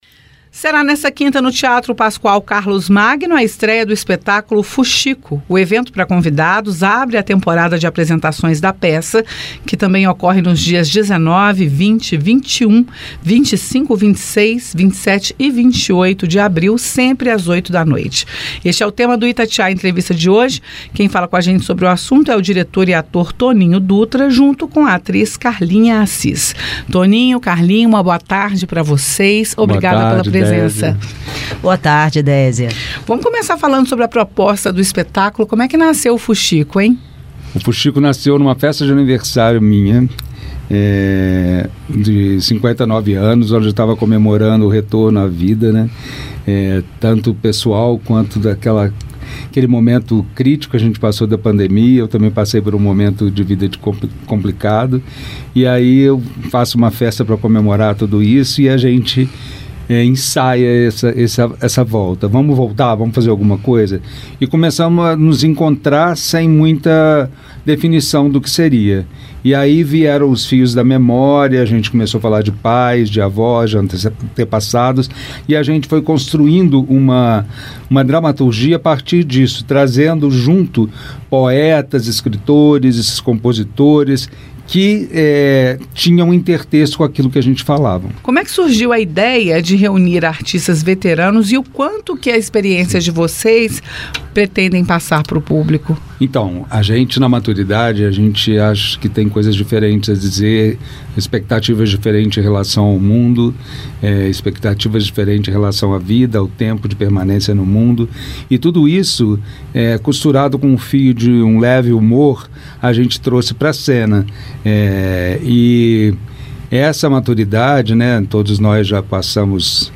Itatiaia-Entrevista-FUXICO.mp3